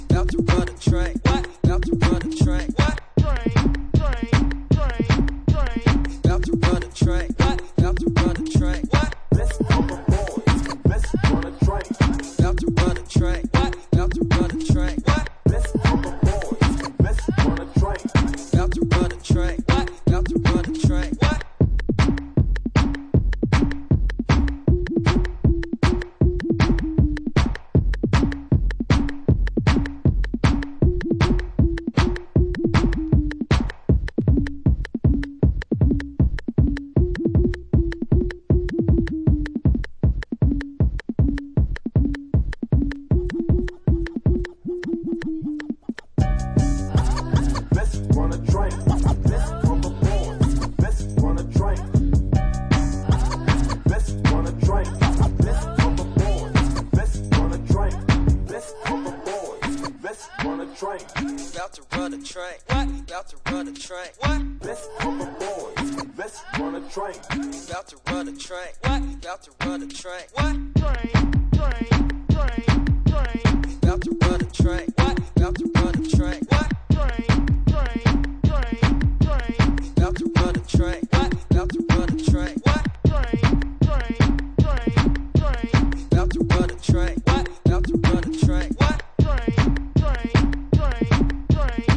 Five tracks of booty shaking, sample laden, jit tech.
Electro